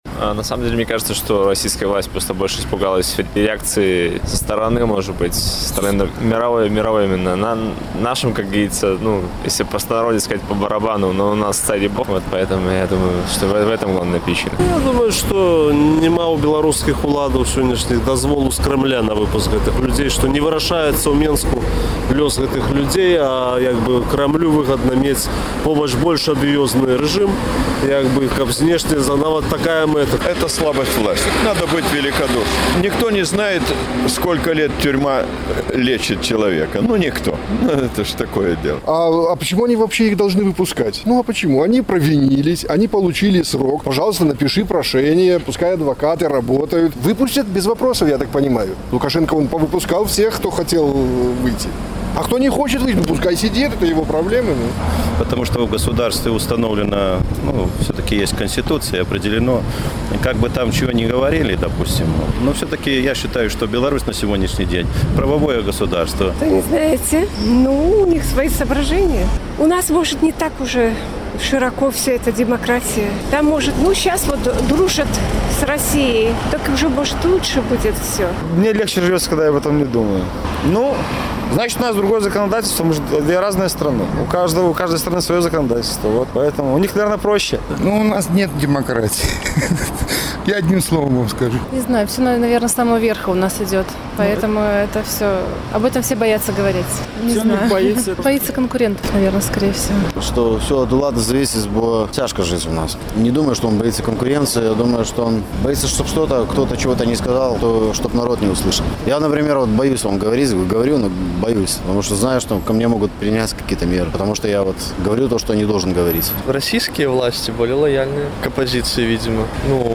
Чаму, на вашу думку, Лукашэнка не вызваляе беларускіх палітвязьняў? Адказваюць гарадзенцы